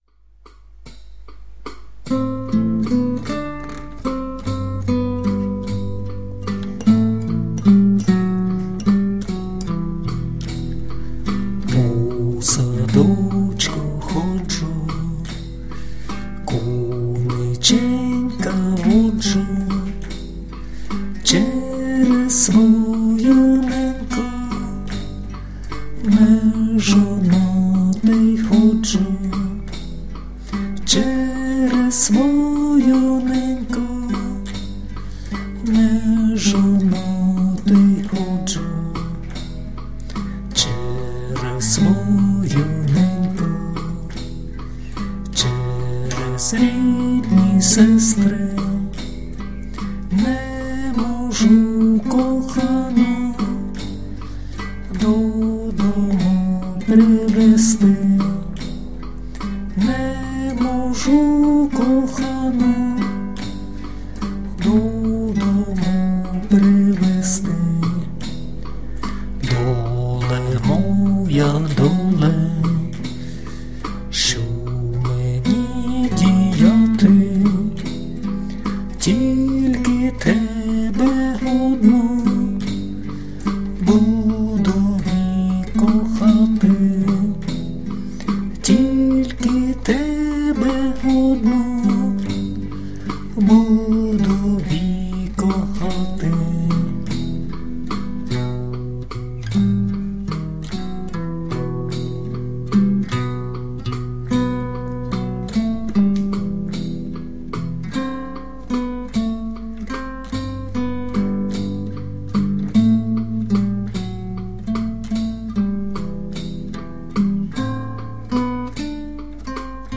../icons/poviyvit.jpg   Українська народна пiсня